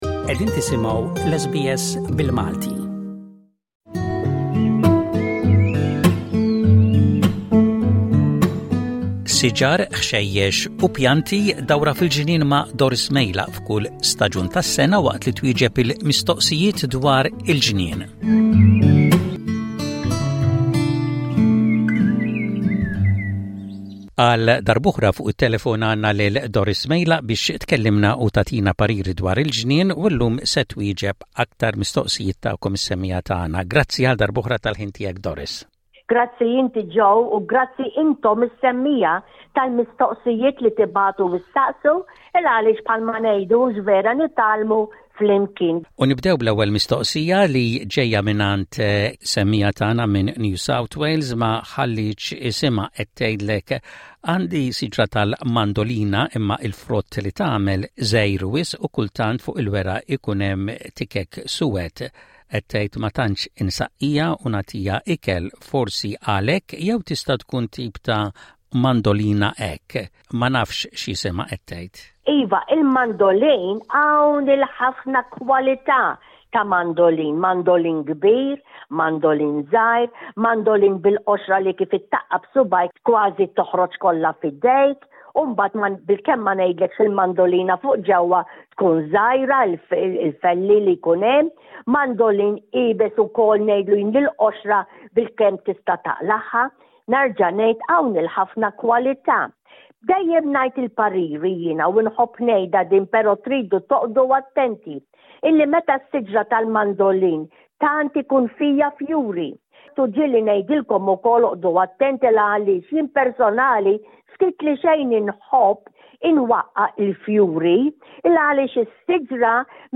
Fil-ġnien | mistoqsijiet u tweġibiet